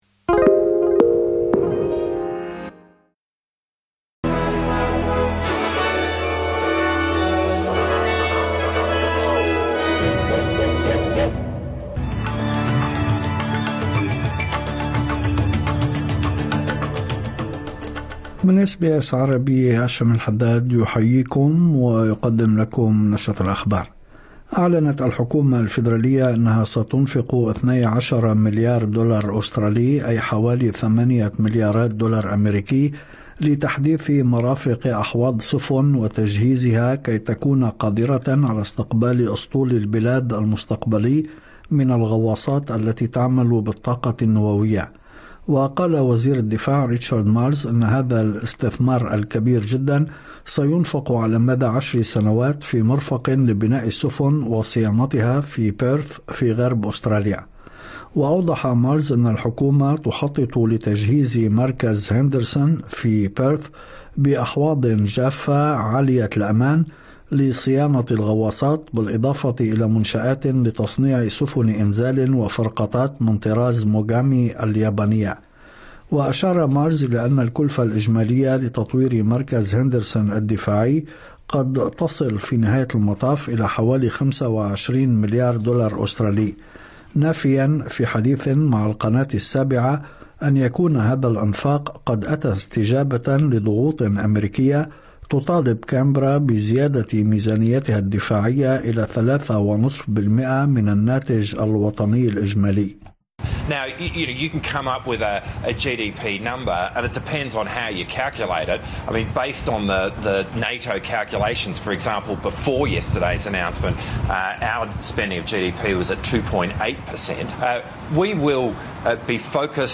نشرة أخبار الظهيرة 15/09/2025